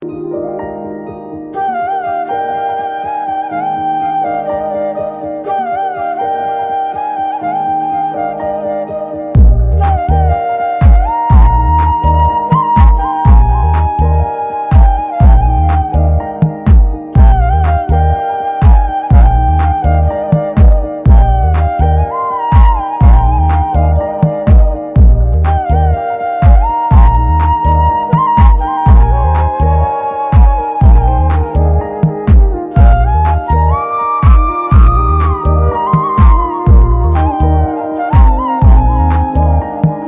The Enchanting Sound of Flute Instrumentals